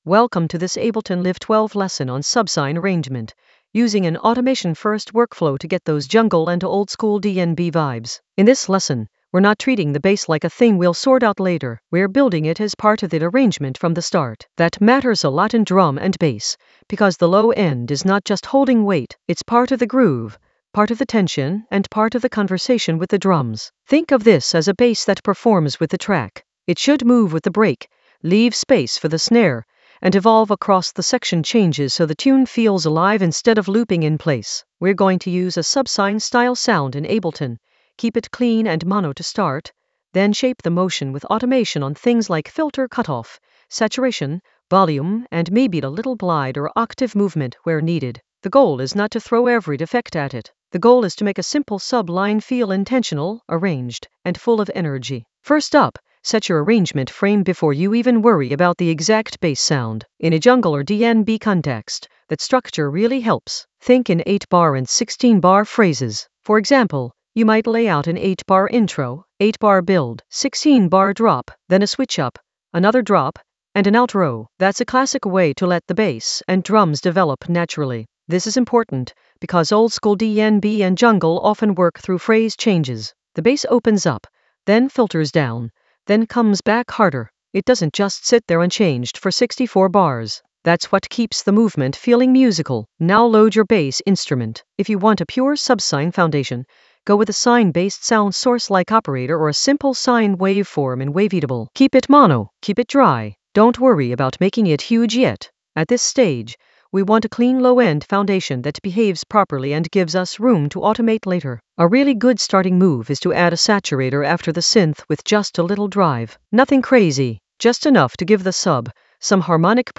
An AI-generated intermediate Ableton lesson focused on Subsine in Ableton Live 12: arrange it with automation-first workflow for jungle oldskool DnB vibes in the FX area of drum and bass production.
Narrated lesson audio
The voice track includes the tutorial plus extra teacher commentary.